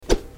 Download Slash sound effect for free.
Slash